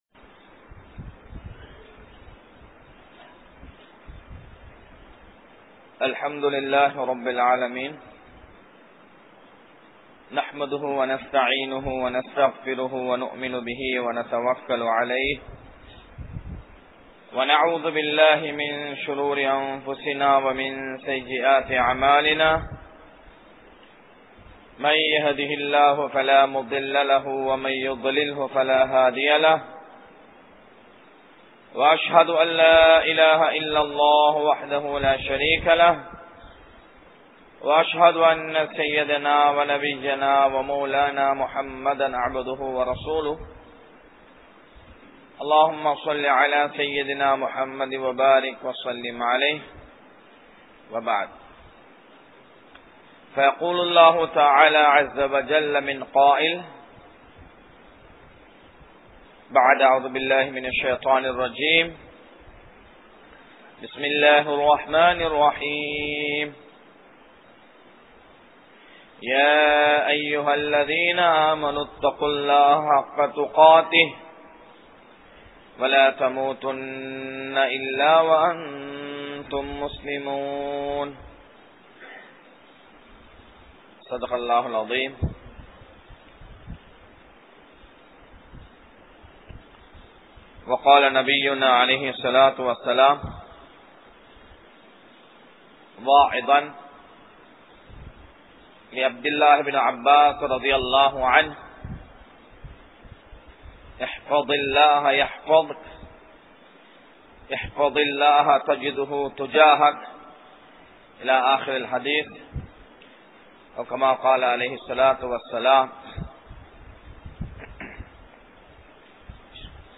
Emaanai Palap Paduthugal | Audio Bayans | All Ceylon Muslim Youth Community | Addalaichenai